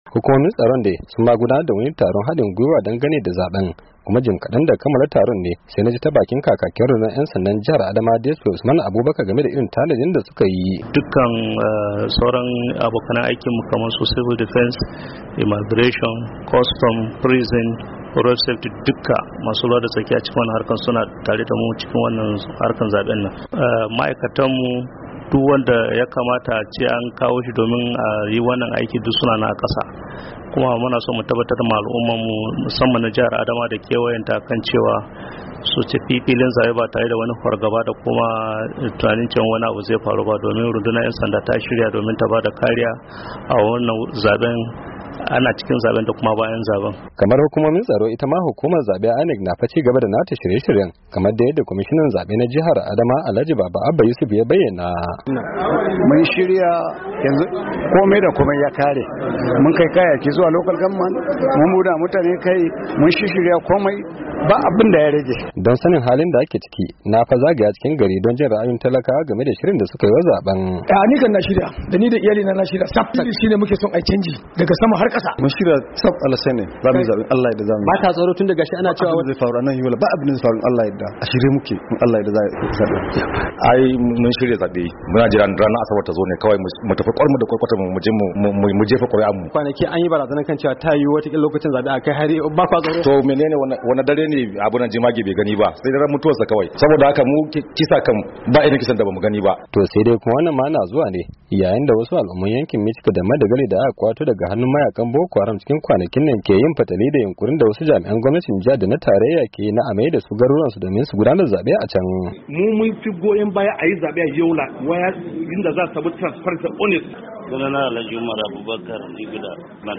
Itama hukumar zaben Najeriyar INEC tace, tana kan gudanar da shirye-shirye domin gudanar da wannan zabe kamar yadda wakilin na muryar Amurka yayi hira da kwamishinan zaben jihar Adamawan Alhaji Baba Abba Yusuf.